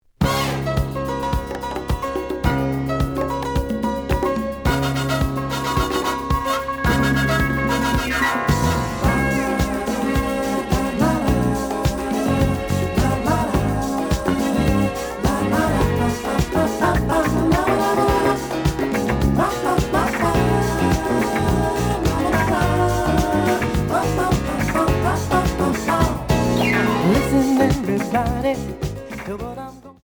(Stereo)
試聴は実際のレコードから録音しています。
●Genre: Disco